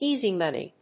アクセントéasy móney